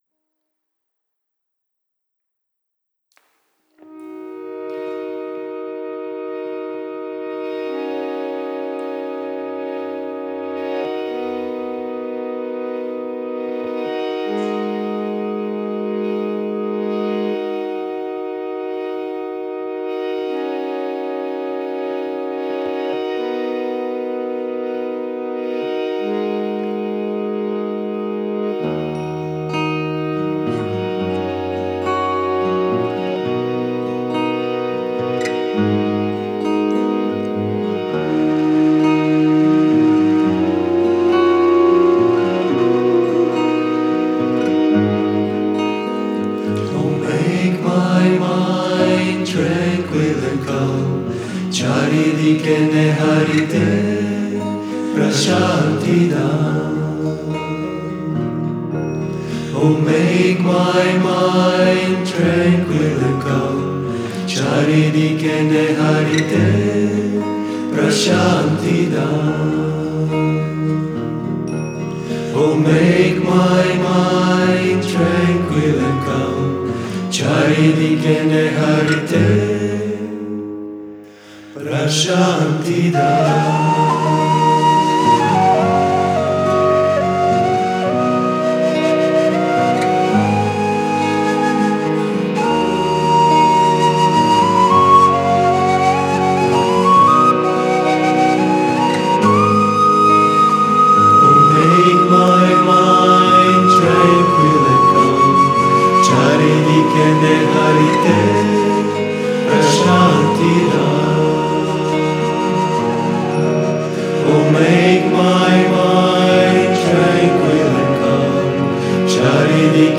Koncert meditativne muzike grupe